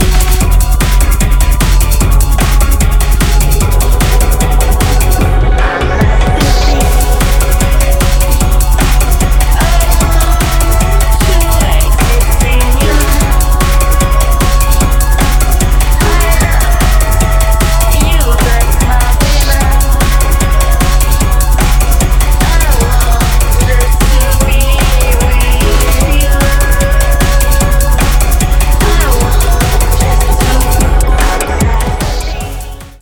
• Genre : hard techno, hard bounce, dark techno, dark trance
Free HardBounce adaptation